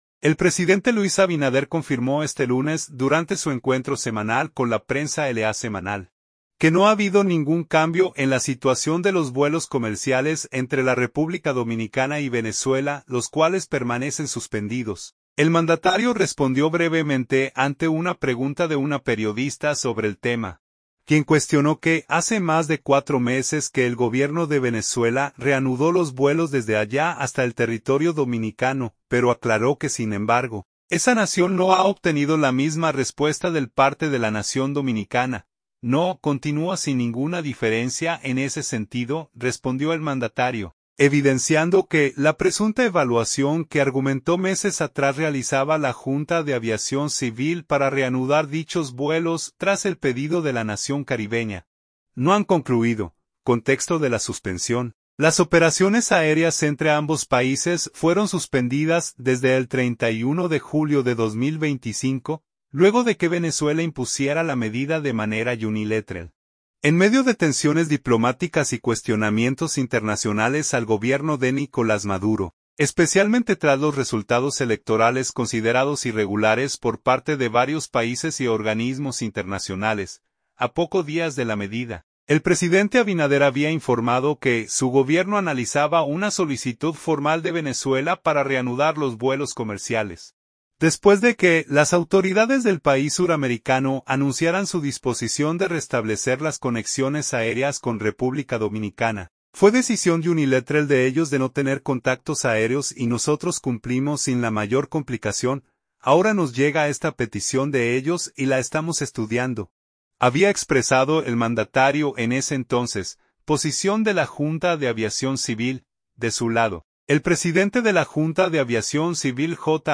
SANTO DOMINGO. – El presidente Luis Abinader confirmó este lunes durante su encuentro semanal con la prensa LA Semanal, que no ha habido ningún cambio en la situación de los vuelos comerciales entre la República Dominicana y Venezuela, los cuales permanecen suspendidos.
El mandatario respondió brevemente ante una pregunta de una periodista sobre el tema, quien cuestionó que hace más de cuatro meses que el Gobierno de Venezuela reanudó los vuelos desde allá hasta el territorio dominicano, pero aclaró que, sin embargo, esa nación no ha obtenido la misma respuesta del parte de la nación dominicana.